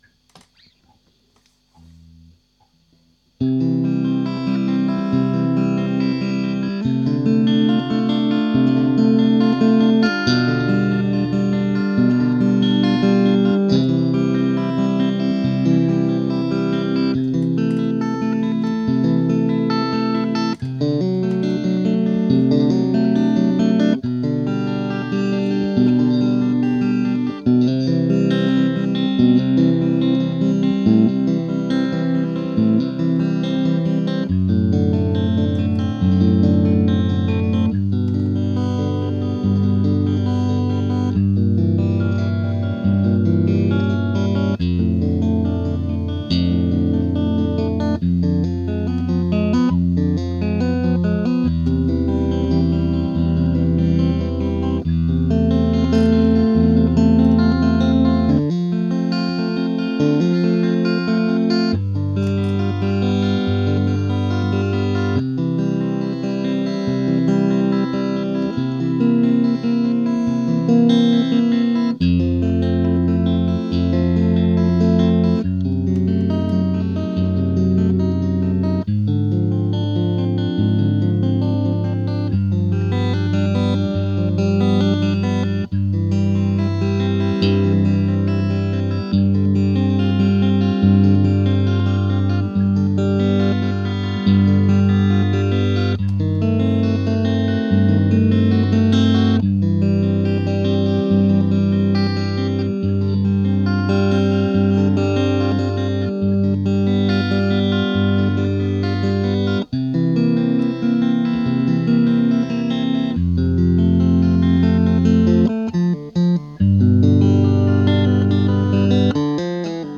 J'en profite donc pour partager de vieux enregistrements à la qualité...très imparfaite tant au niveau du son que musicale :newblush: .
JS Bach - Prélude No1 en Do Majeur, BWV 846: